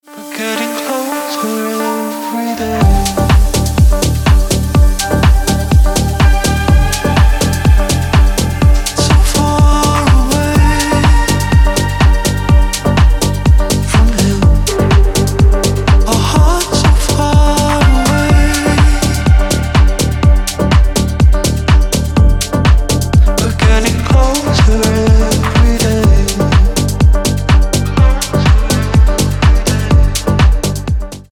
Electronic
спокойные
расслабляющие
Стиль: deep house